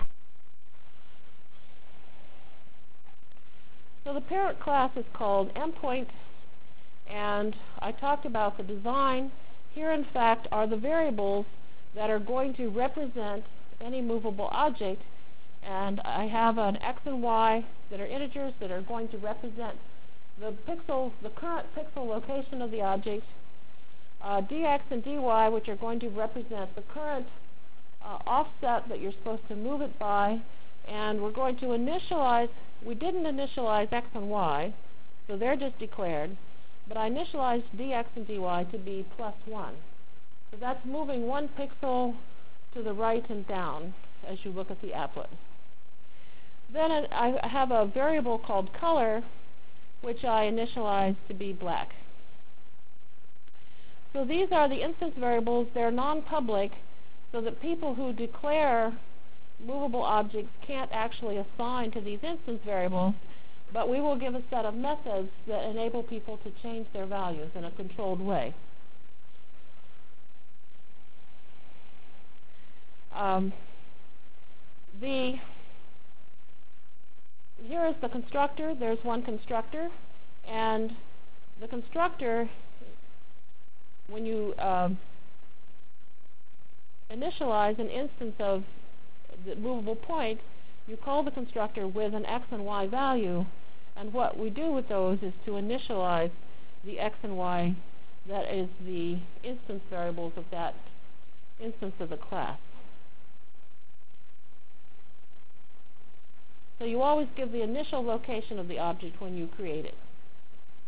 From Jan 27 Delivered Lecture for Course CPS616 -- Java Lecture 2 -- Basic Applets and Objects CPS616 spring 1997 -- Jan 27 1997.